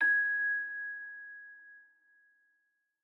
celesta1_8.ogg